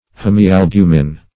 hemialbumin - definition of hemialbumin - synonyms, pronunciation, spelling from Free Dictionary
Hemialbumin \Hem`i*al*bu"min\, n. [Hemi- + albumin.]